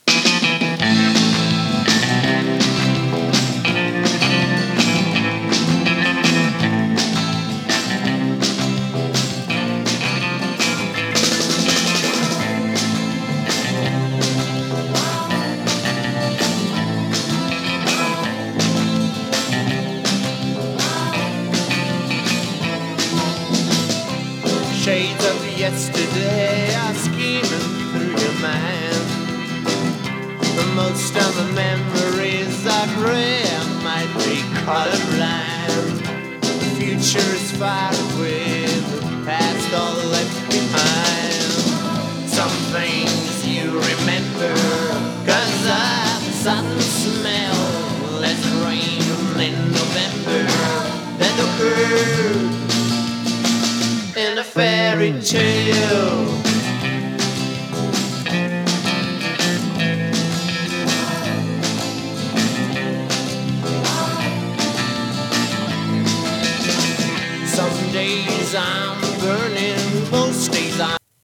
60年代のガレージ/サイケを昇華したサウンドと個性的な唄声でニューウェイヴィーな雰囲気も漂わせる作品です！